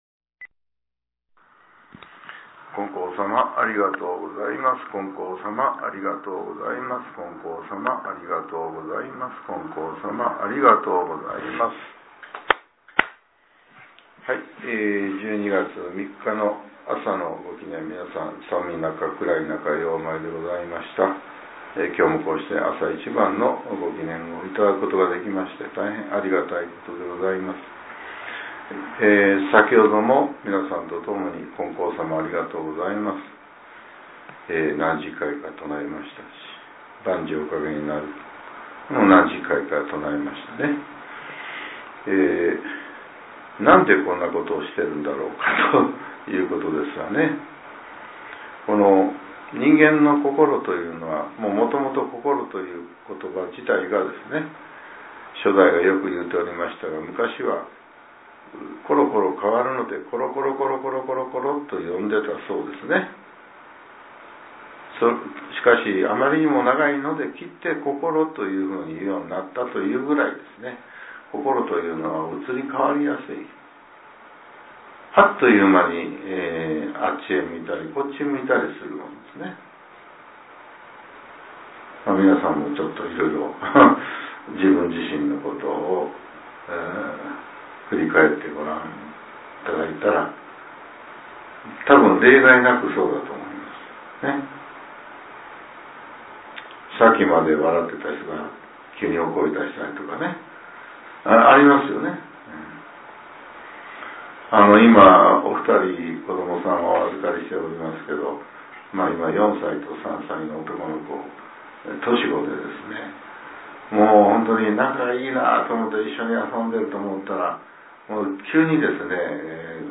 令和７年１２月３日（朝）のお話が、音声ブログとして更新させれています。